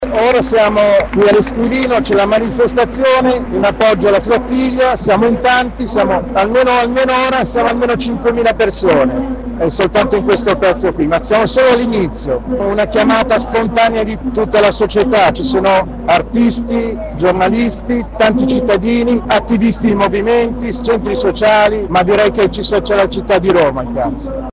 sonoro-manifestazione.mp3